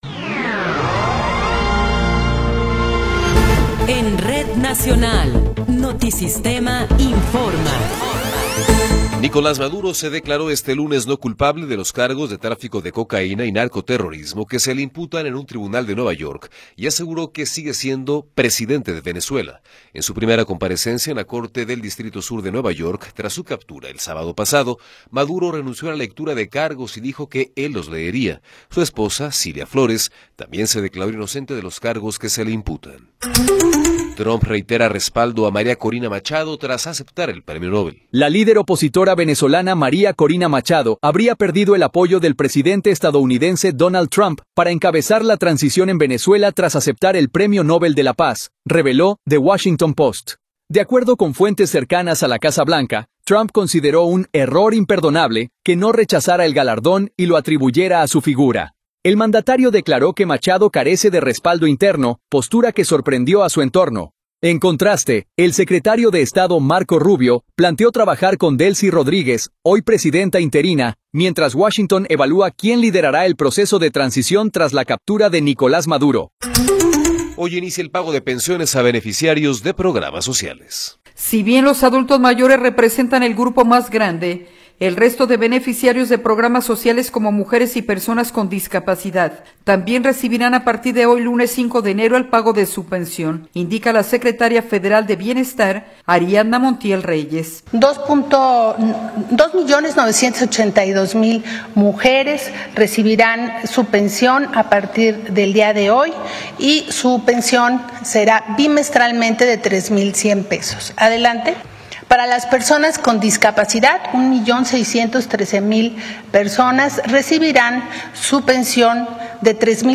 Noticiero 13 hrs. – 5 de Enero de 2026